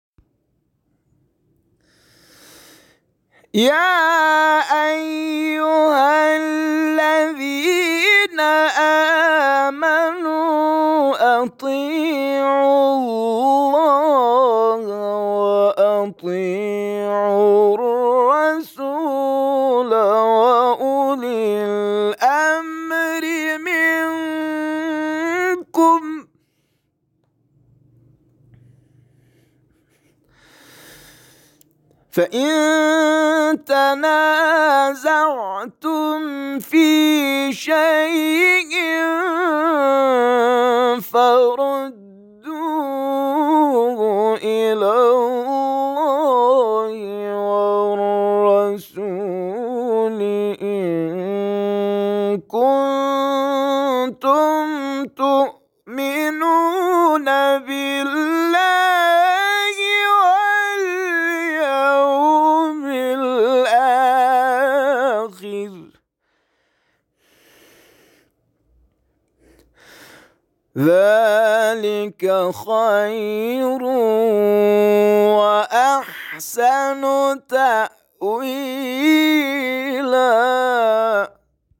تلاوت نفرات برتر مسابقه از دید داوران:
تلاوت